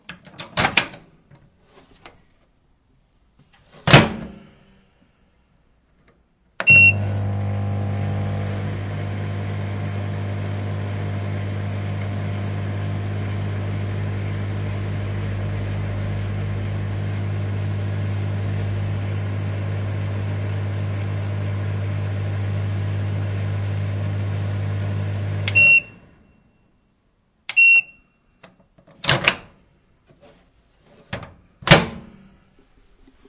微波炉（开/关+操作声音）
描述：微波炉的声音。打开/关闭门，按钮发出哔哔声，哼唱......用Zoom H2n记录
Tag: 开放 哼唱 关闭 嗡嗡声 烤箱 经营 哔哔声 微波 加热 哔哔声 音调 声音